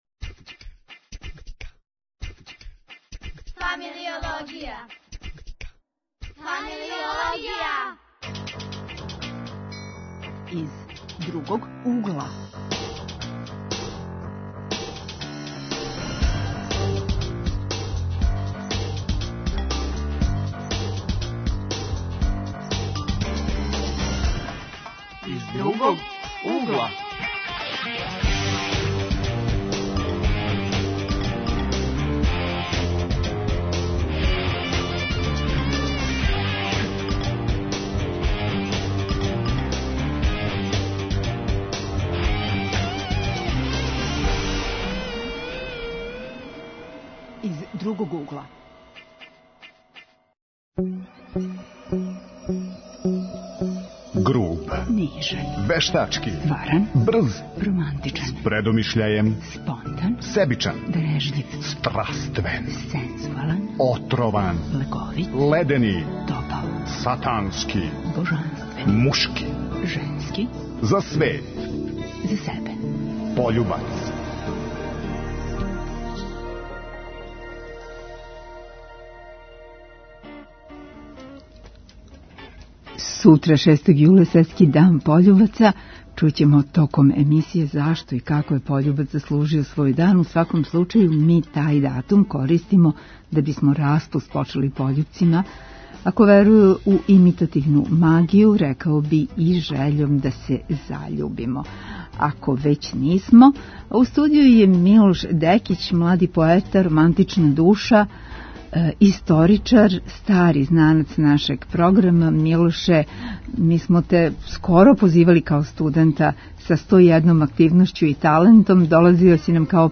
Гости у студију биће заљубљени студенти.